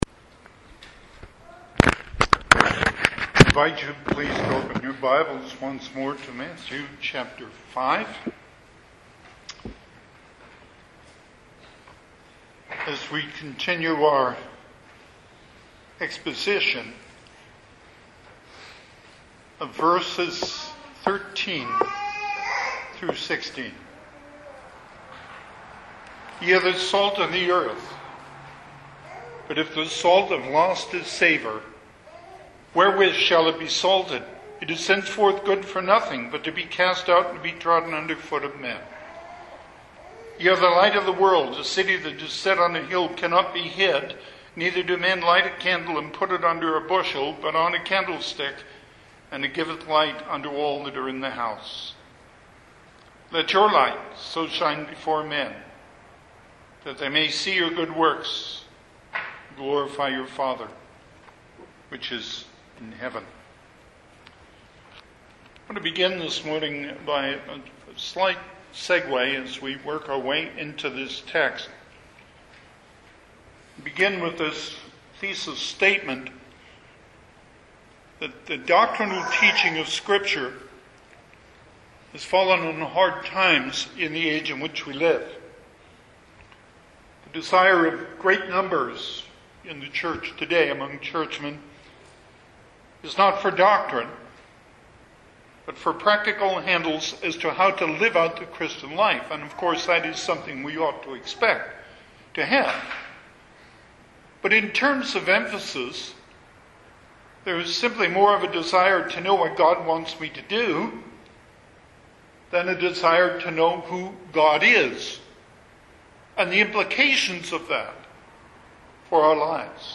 Passage: Matthew 5:14-16 Service Type: Sunday AM